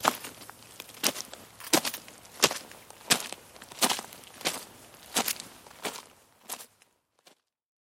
Звук ходьбы пешком по железной дороге (между рельс) (00:08)